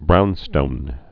(brounstōn)